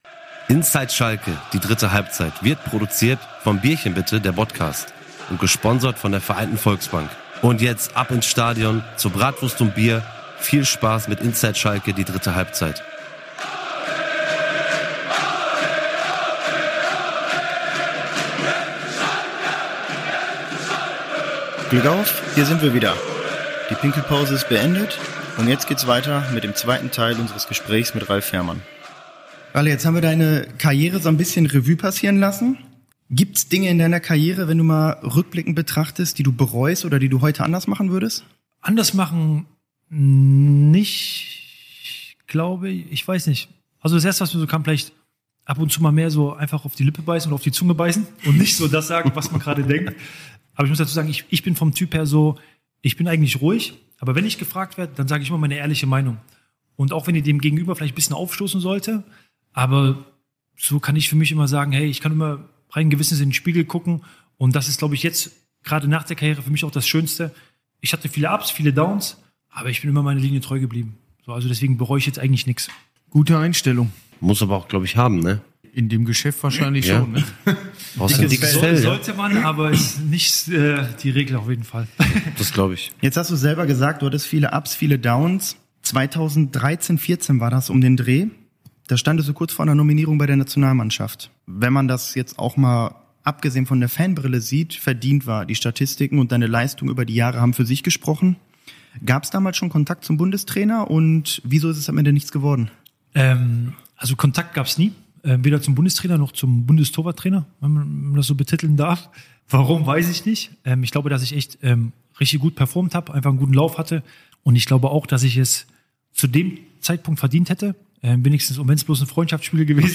Beschreibung vor 6 Monaten Im zweiten Teil unseres exklusiven Interviews mit Ralf Fährmann tauchen wir noch tiefer ein in die Welt des FC Schalke 04 – aus der Perspektive eines Spielers, der den Verein wie kaum ein anderer kennt und geprägt hat.